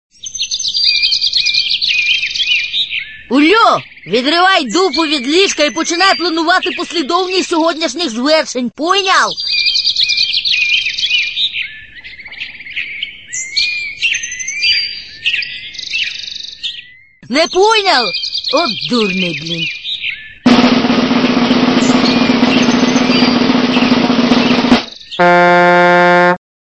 Тип: рінгтони